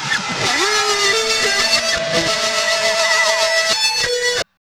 Index of /90_sSampleCDs/Spectrasonics - Bizarre Guitar/Partition F/10 FEEDBACK